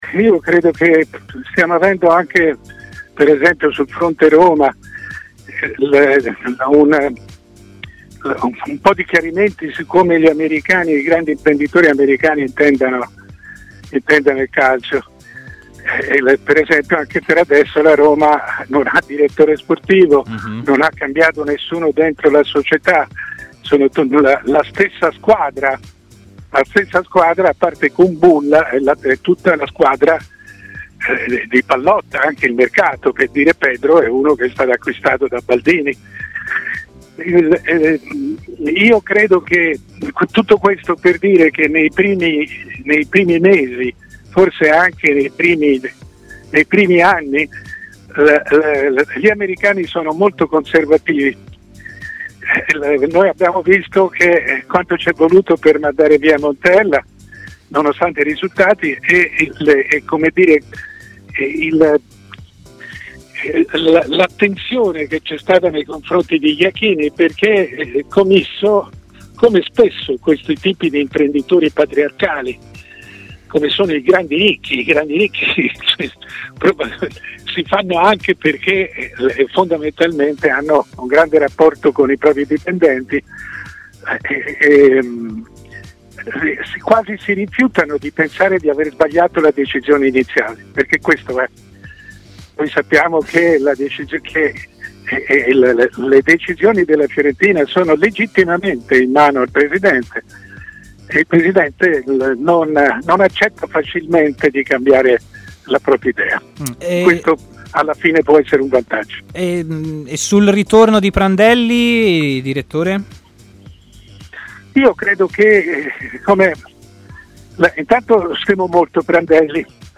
Il direttore Mario Sconcerti è intervenuto in diretta a Stadio Aperto, trasmissione di TMW Radio partendo dal ritorno di Cesare Prandelli alla Fiorentina al posto di Beppe Iachini: "Credo che stiamo avendo un po' di chiarimenti su come i grandi imprenditori americani intendano il calcio, anche sul fronte Roma: lì per esempio non c'è il nuovo ds, non hanno cambiato nessuno in società e anche la squadra praticamente è di Pallotta.